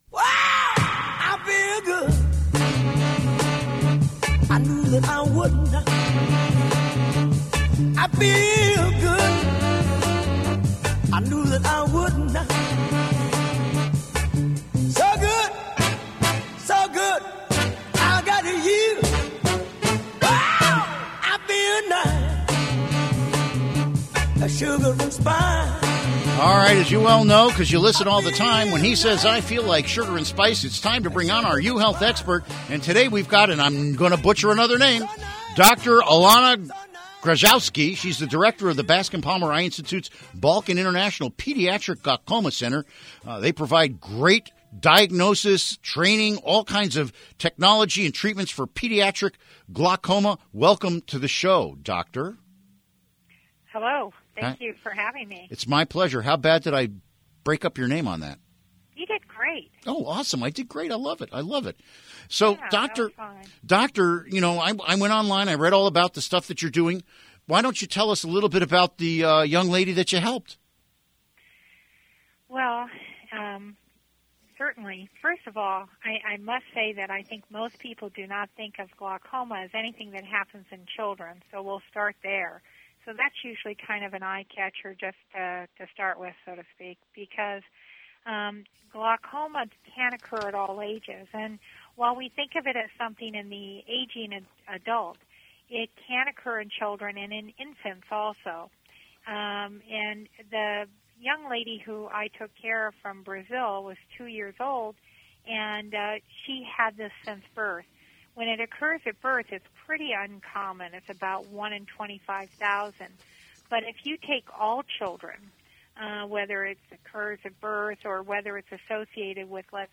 Interview Segment Episode 366: 04-28-16 Download Now!